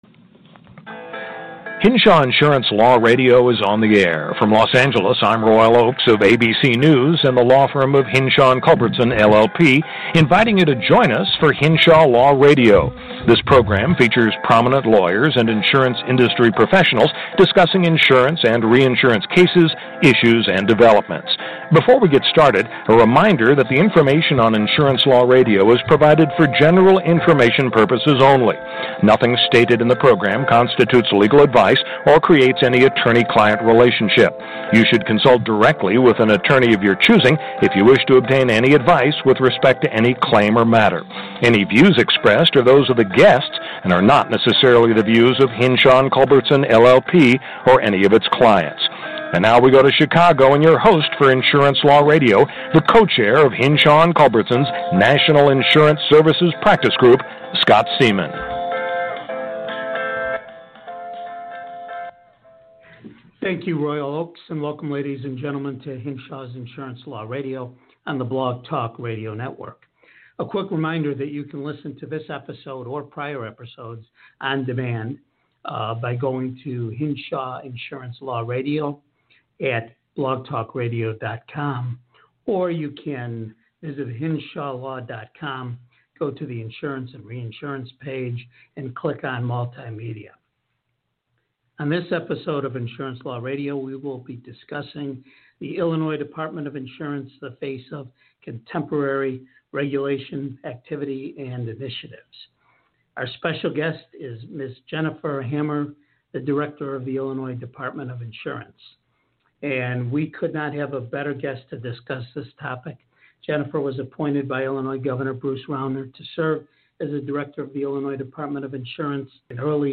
Hinshaw Insurance Law Radio Interviews Jennifer Hammer, Director of the Illinois Department of Insurance